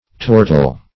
Search Result for " tortile" : The Collaborative International Dictionary of English v.0.48: Tortile \Tor"tile\, a. [L. tortilis, fr. torquere, tortum, to twist: cf. F. tortile.]